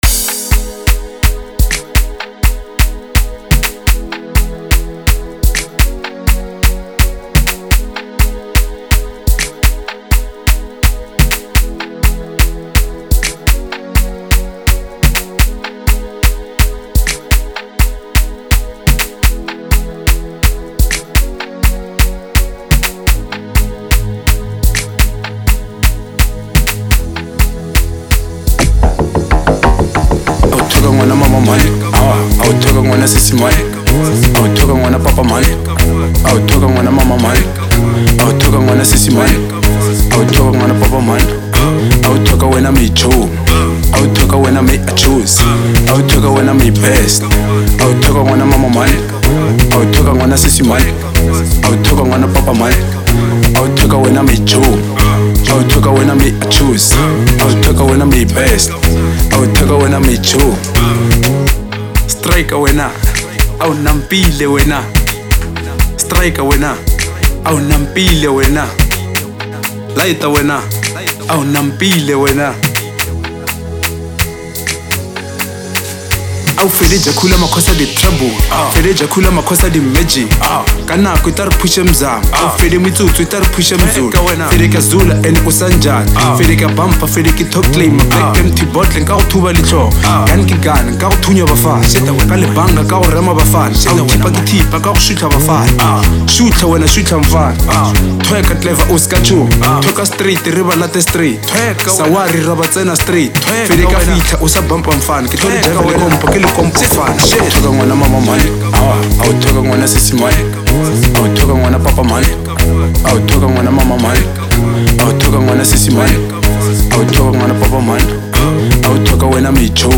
04:45 Genre : Local House Size